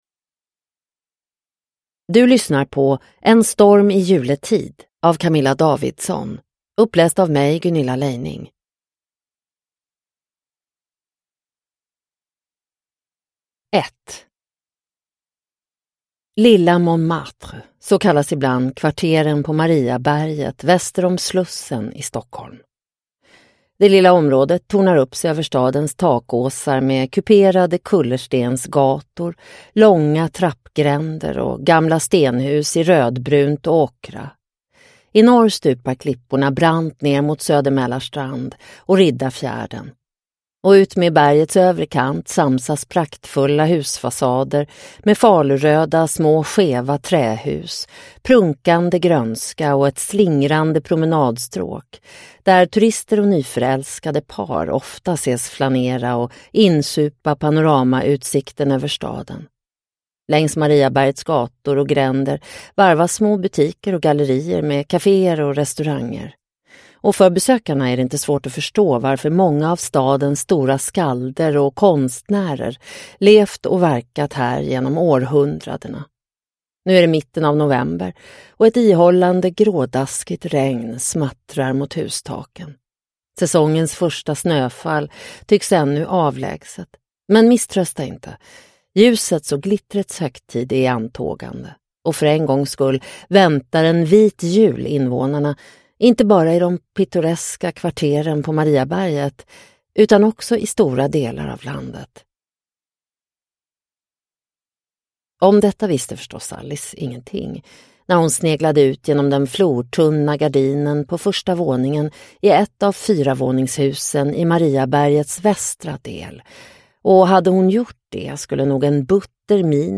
En storm i juletid – Ljudbok – Laddas ner